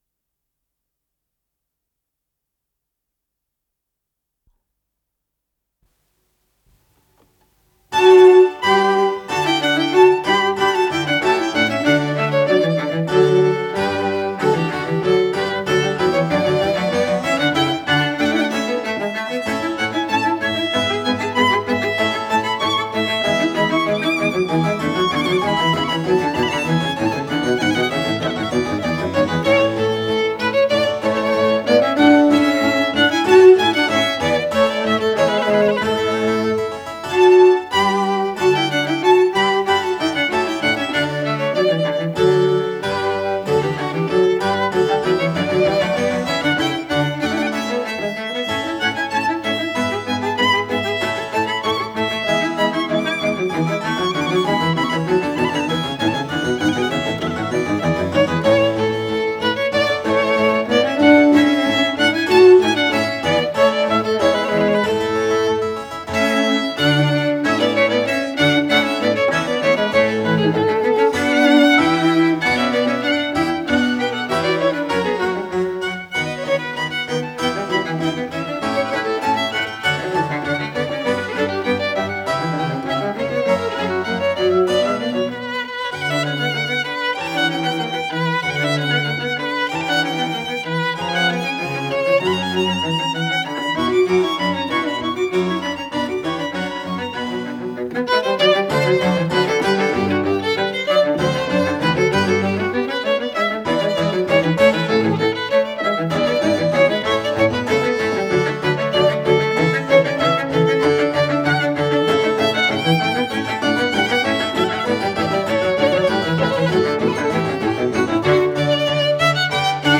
Аллегро ассаи
ИсполнителиКамерный ансамбль "Барокко"
скрипка
виолончель соло
клавесин
виолончель континуо
ВариантДубль моно